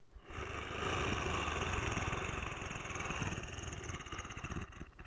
growl.ogg